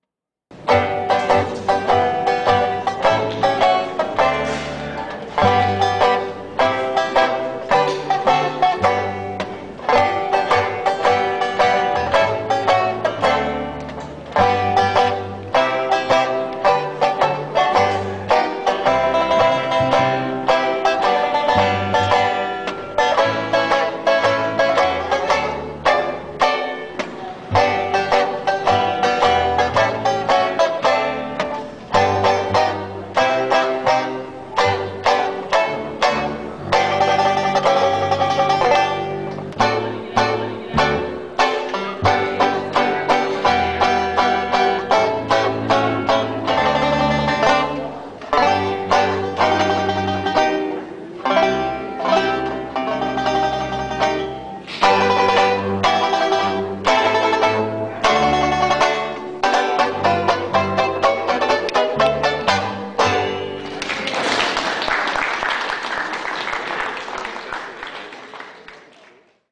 8-beat intro.